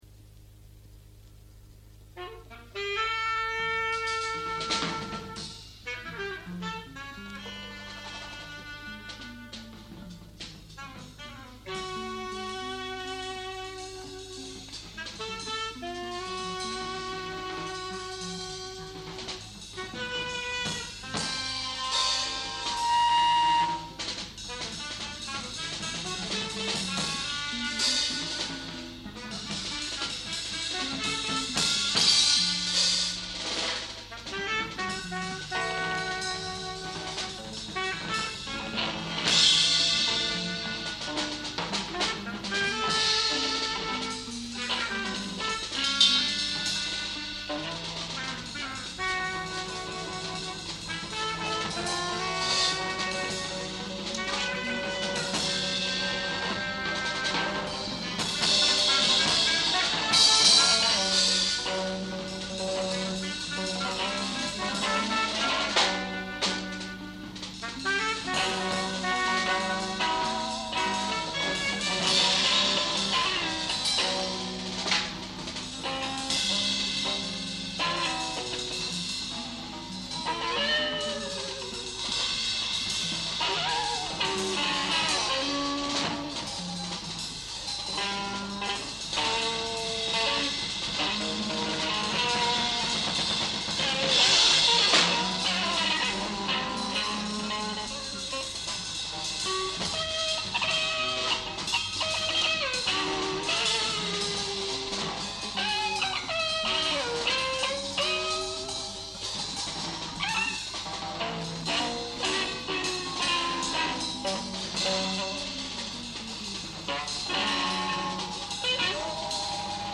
Le free jazz à Clermont-Ferrand